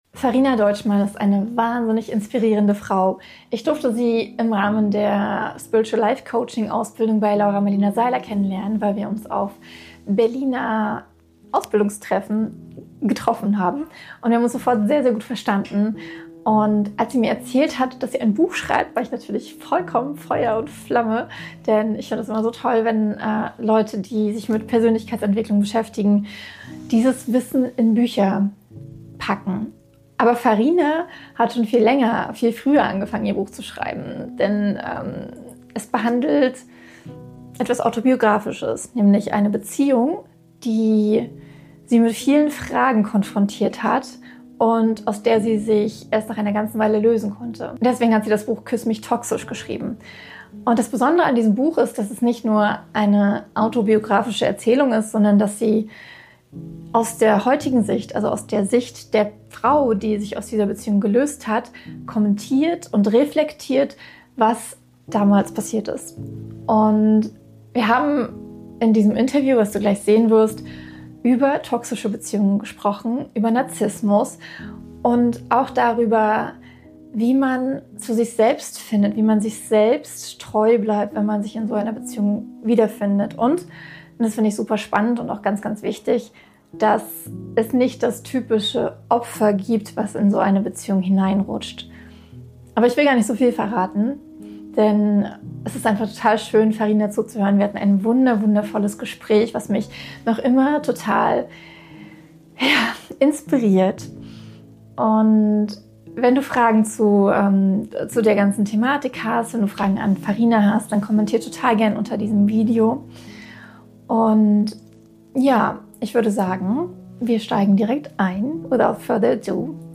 Gleichzeitig betrachten wir auch die Rolle der eigenen Muster, Trigger und Grenzen – und wie viel Klarheit entsteht, wenn man versteht, wer man selbst eigentlich ist. Ein intensives, ehrliches, warmes Gespräch, das dir Orientierung geben kann – egal ob du selbst betroffen bist, jemanden begleiten möchtest oder einfach mehr über dieses Thema verstehen willst.